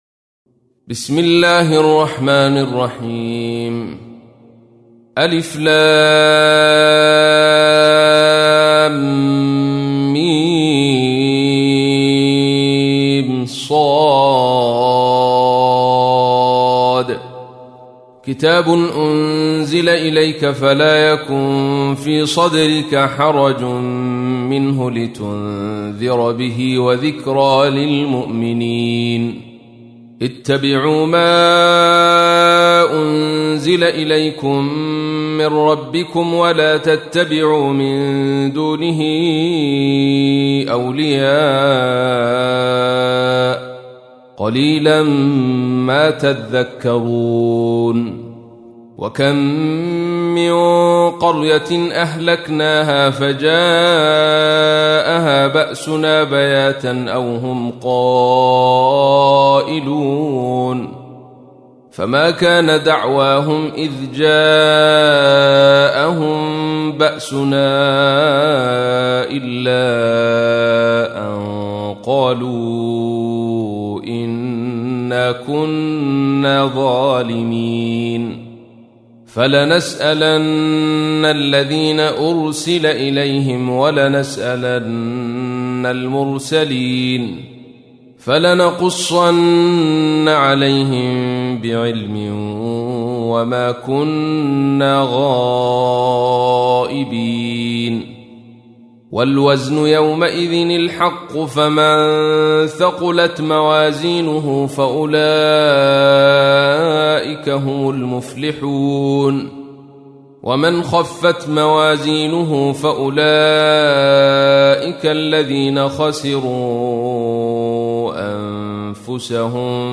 تحميل : 7. سورة الأعراف / القارئ عبد الرشيد صوفي / القرآن الكريم / موقع يا حسين